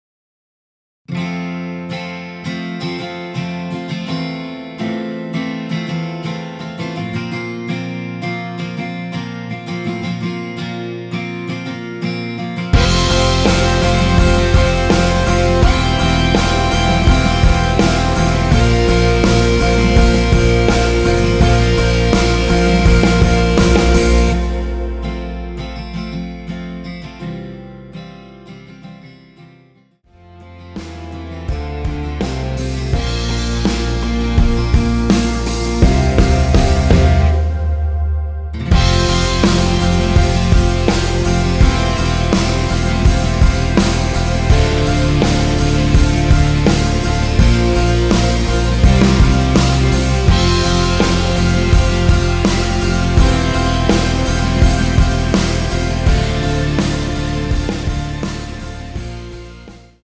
원키에서(+5)올린 (1절앞+후렴)으로 진행되게 편곡된 MR입니다.
앞부분30초, 뒷부분30초씩 편집해서 올려 드리고 있습니다.
중간에 음이 끈어지고 다시 나오는 이유는